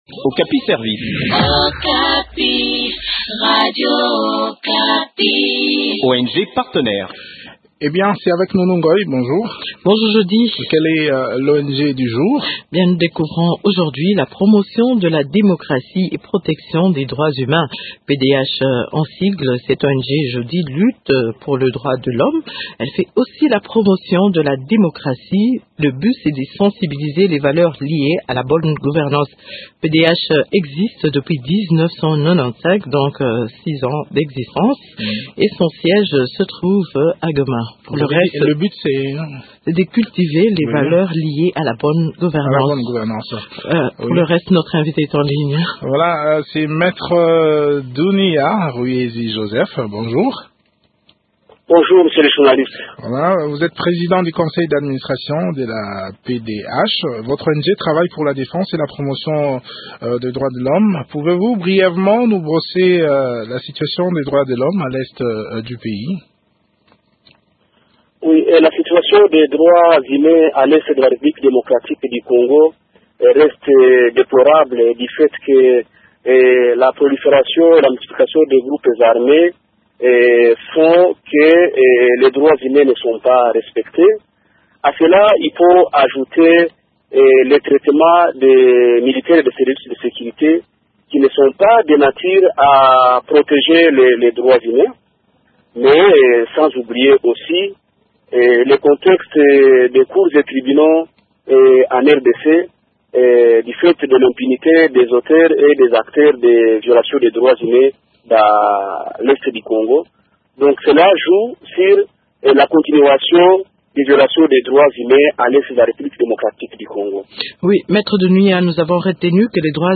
Découvrez les différentes activités de cette structure dans cet entretien